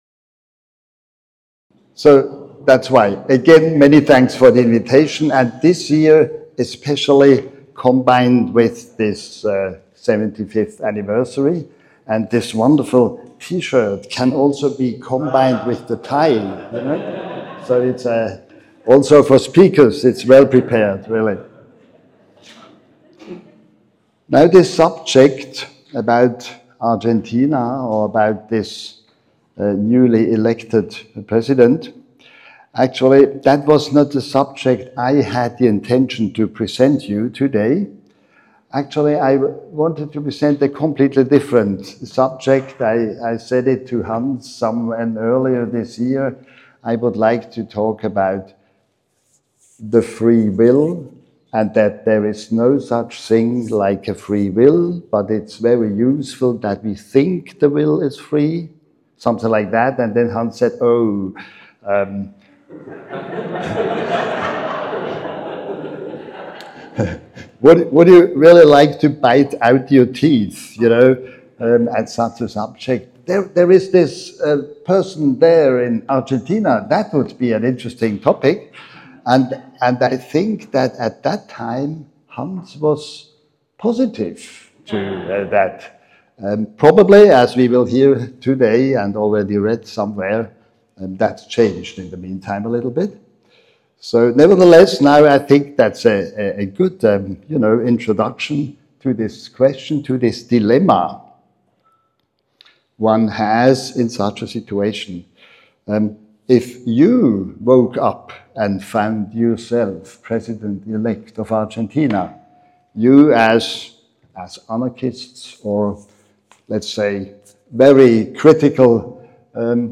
This talk is from the recently-concluded 18th annual 2024 Annual Meeting of the PFS (Sept. 19–24, 2024, Bodrum, Turkey).